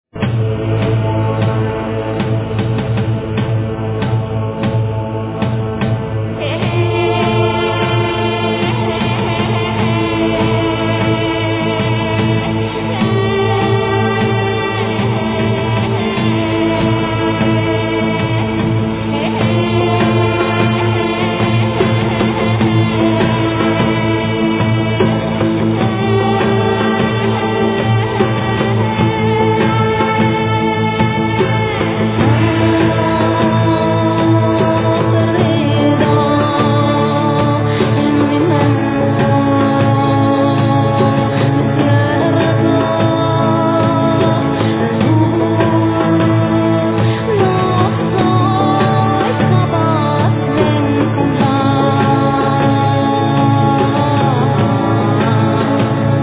ゴシック・フォーク界のカリスマバンド名作復刻盤第２弾
女性ヴォーカル、ドラム＆ベースの男性
Voice, Hardy Gardy, Bells, Percussions, Flute
Bass, Darbuka, Drums, Voice
Bagpipes, Flute, Whistles
Acoustic guitars, Bouzouki
violin